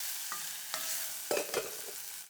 SFX_Cooking_01.wav